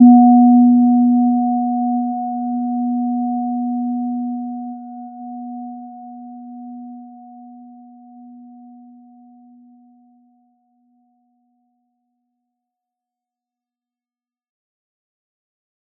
Gentle-Metallic-1-B3-p.wav